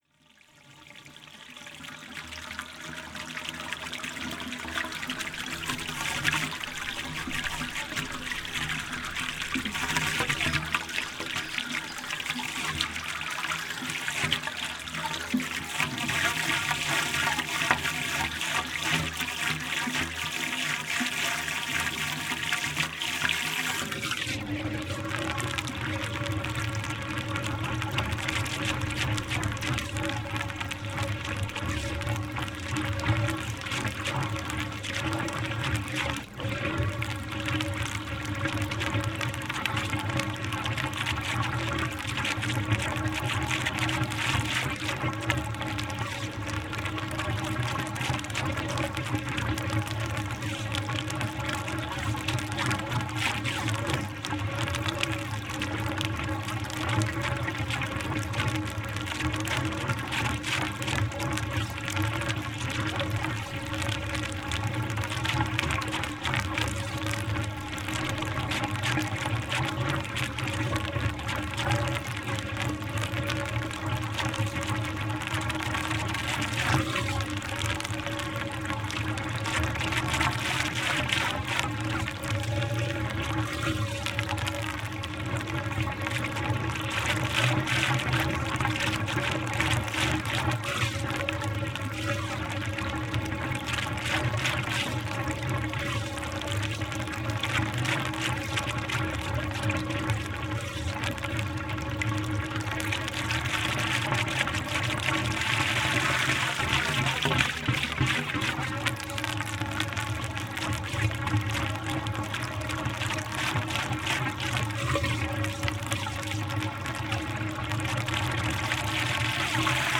A água do lavadouro junto ao Chafariz das Laginhas é fria e gargareja.
NODAR.00556 – Calde: Escoamento de água de lavadouro junto ao Chafariz das Laginhas (+ Trator) em Paraduça